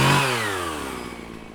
PNChainsword.wav